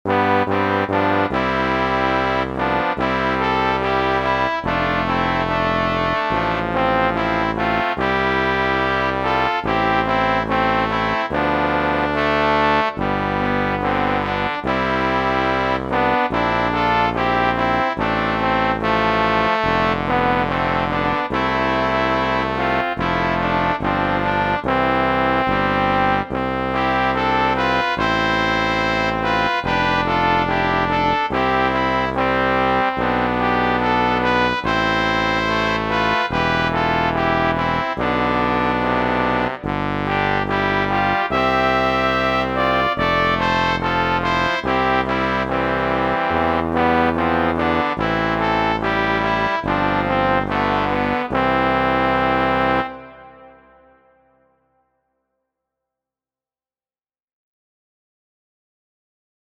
Z       Zusammenspiel im Orchester in C-Dur (alle Instrumente)